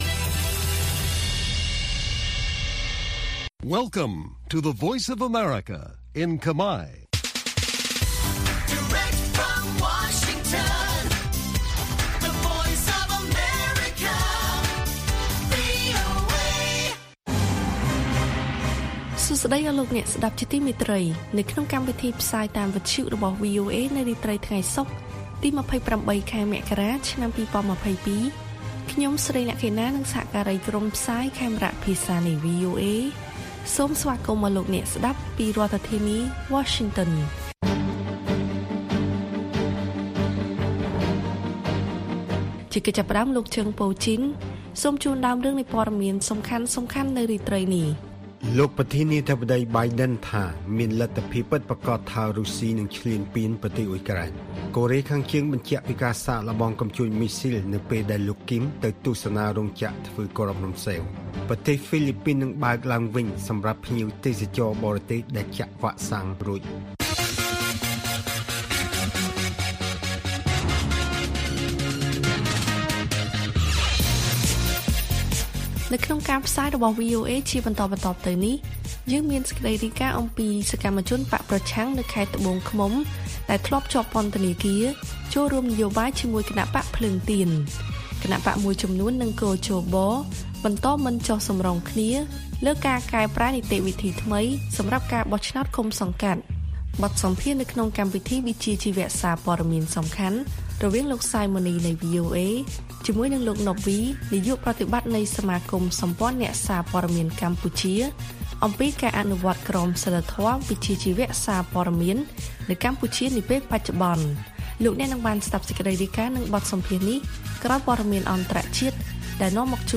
ព័ត៌មានពេលរាត្រី៖ ២៨ មករា ២០២២
បទសម្ភាសន៍នៅក្នុងកម្មវិធី«វិជ្ជាជីវៈសារព័ត៌មានសំខាន់» អំពី«ការអនុវត្តក្រមសីលធម៌វីជ្ជាជីវៈសារព័ត៌មាននៅកម្ពុជាបច្ចុប្បន្ន»។